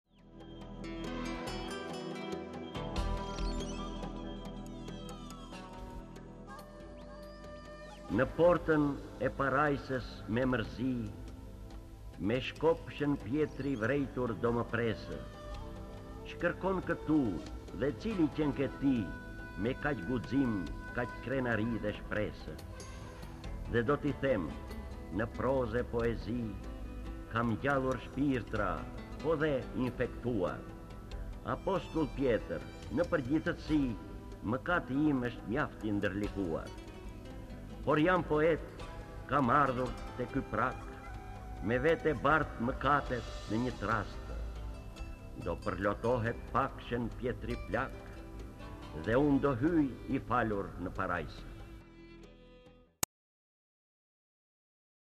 D. AGOLLI - ME SHËN PJETRIN Lexuar nga D. Agolli KTHEHU...